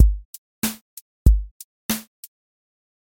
QA Listening Test boom-bap Template: boom_bap_drums_a
• voice_kick_808
• voice_snare_boom_bap
• voice_hat_rimshot
A longer-form boom bap song study with multiple sections, recurring motifs, pattern evolution across the arrangement, and internal edits within repeated patterns.